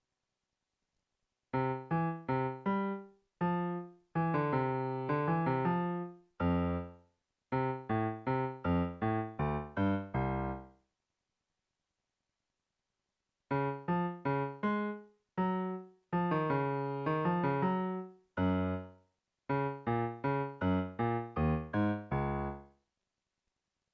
Den lyder (lige) godt i alle tonearter:
temaet først i C og så i Cis